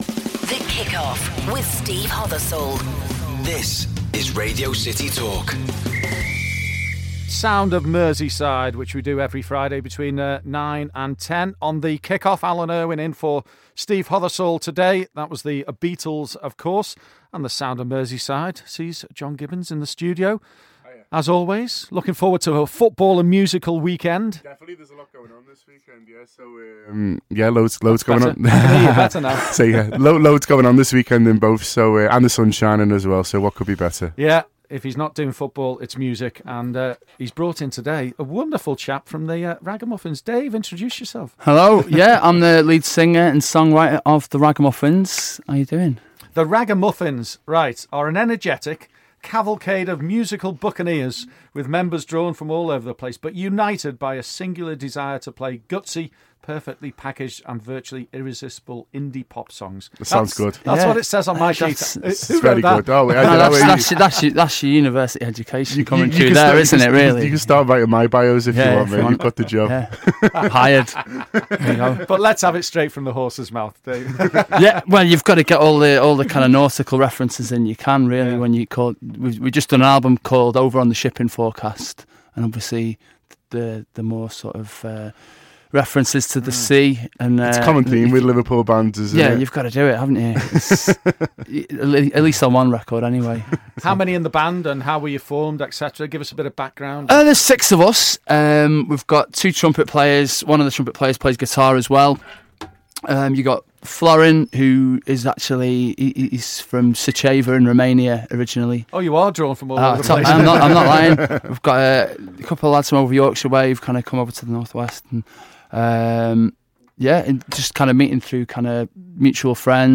come into the studio